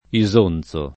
Isonzo [ i @1 n Z o ] top. m.